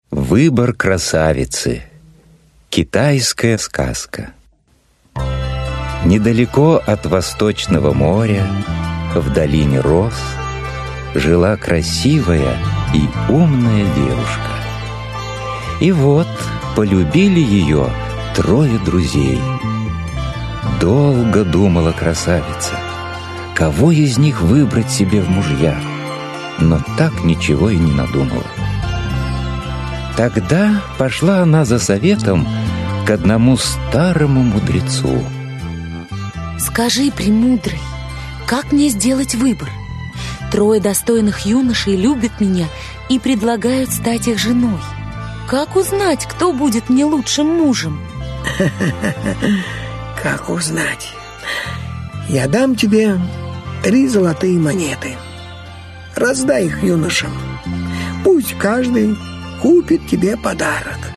Aудиокнига Сказки народов мира в аудиоспектаклях Автор Народное творчество Читает аудиокнигу Лев Дуров.